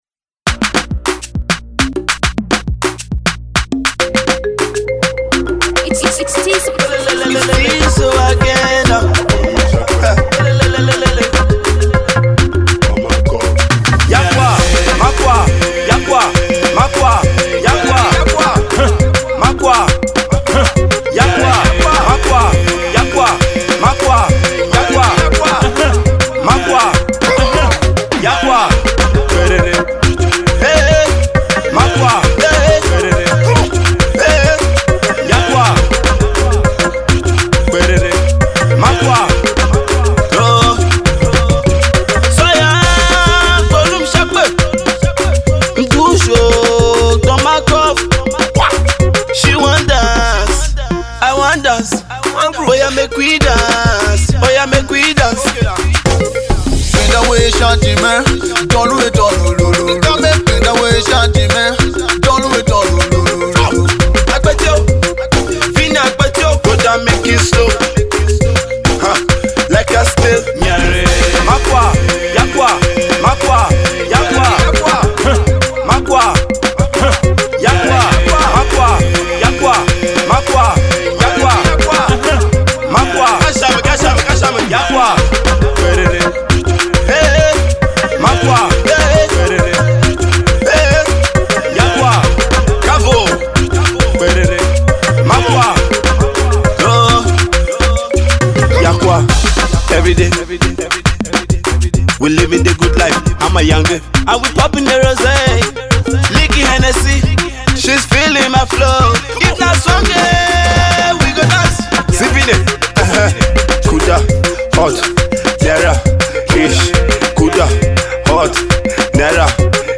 Dancehall Veteran